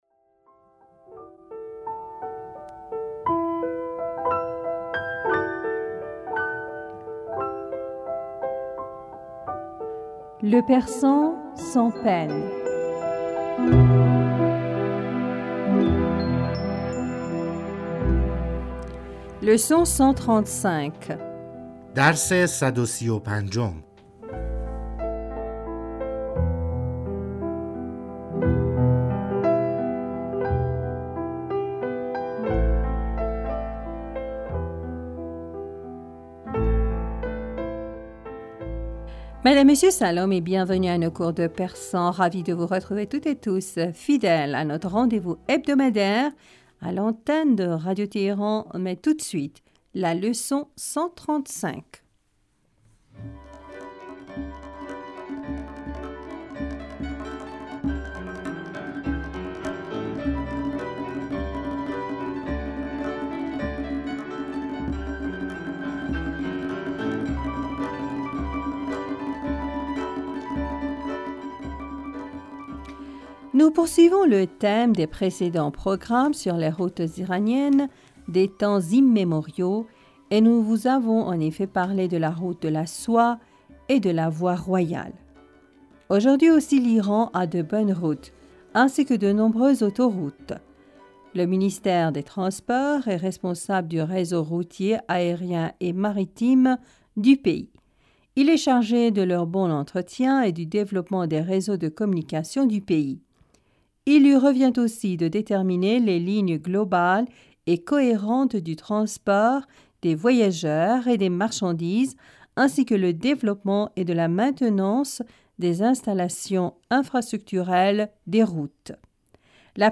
Madame, Monsieur, Salam et bienvenus à nos cours de persan.
Ecoutez et répétez après nous.